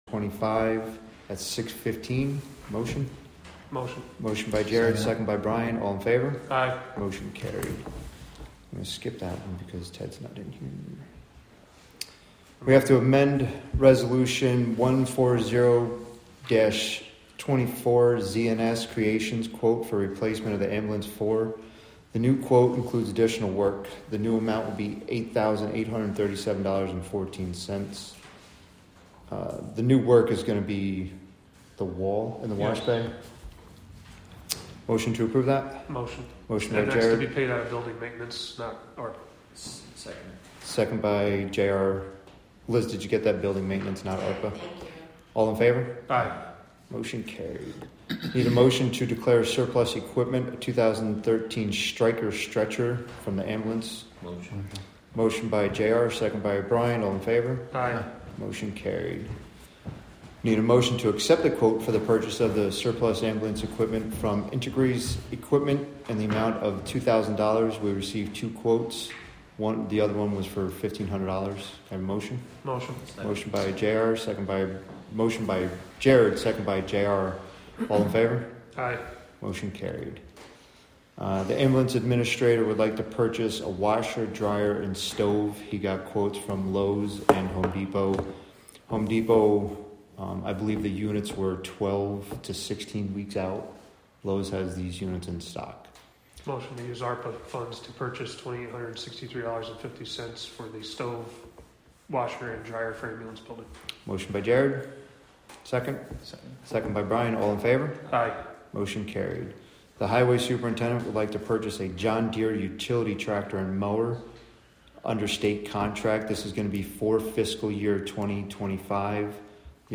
Live from the Town of Catskill: December 18, 2024 Catskill Town Board Meeting (Audio)